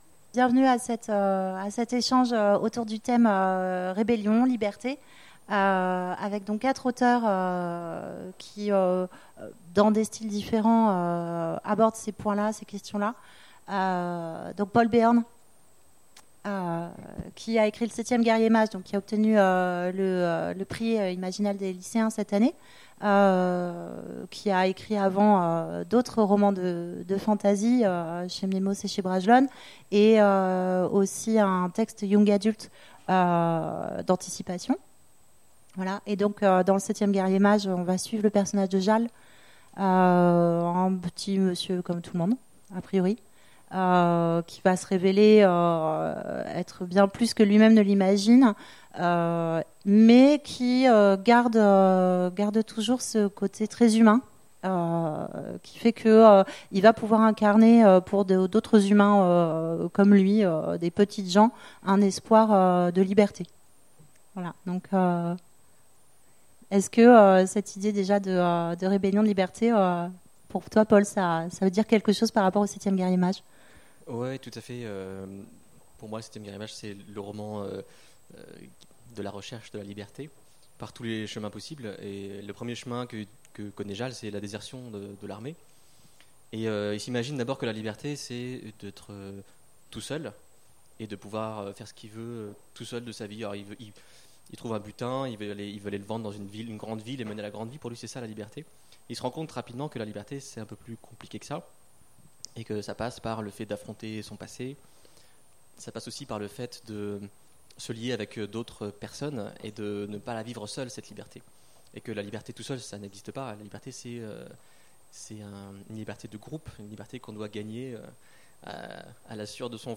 Imaginales 2016 : Conférence Vive la liberté !